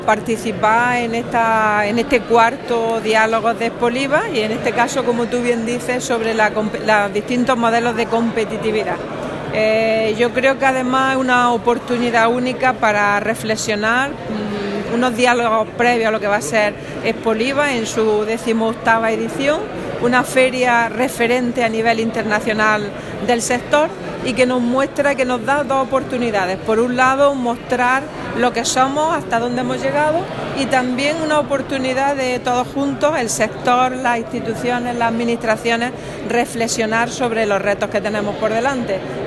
Declaraciones de Carmen Ortiz sobre los Diálogos Expoliva